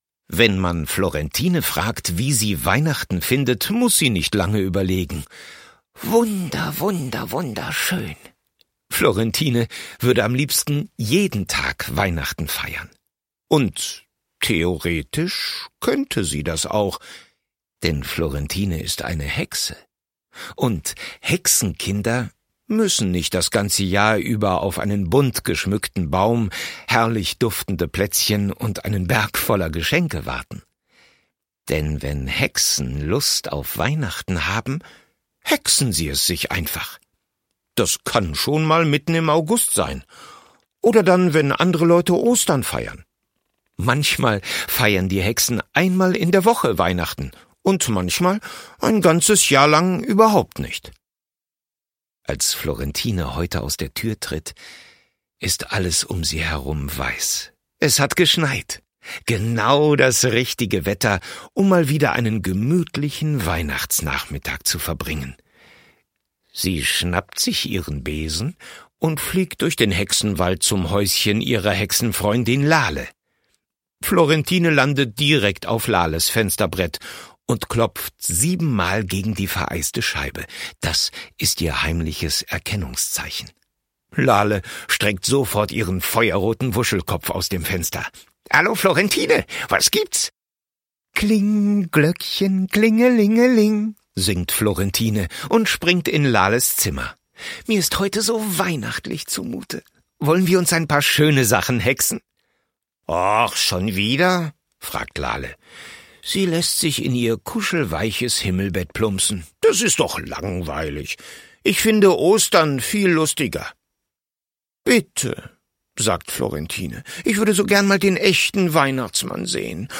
Kinder- / Jugendbuch Vorlesebücher / Märchen